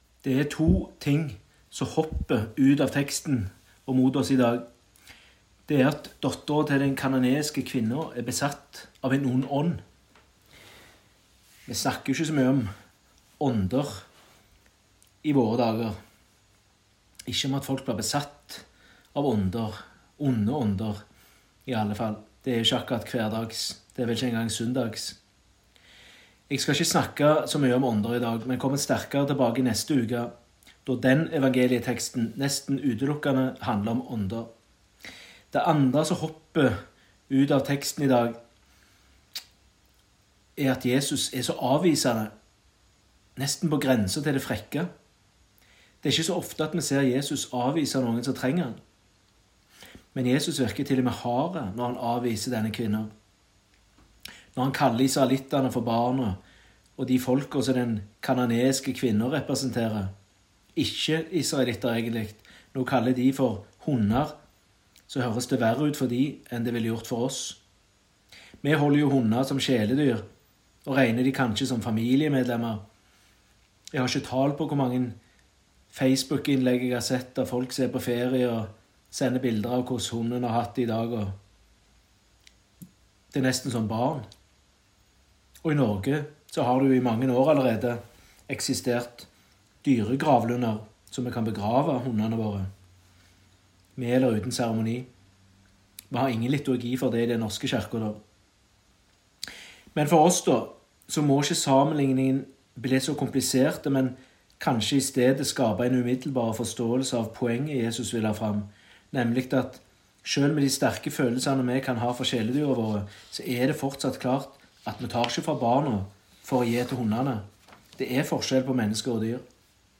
Tekstene Evangelietekst: Matt 15,21–28 Lesetekst 1: 1 Mos 32,24–30 Lesetekst 2: Jak 1,2–8 Utdrag fra talen (Hør hele talen HER ) Folk og dyr Jesus virker hard når han avviser kvinnen.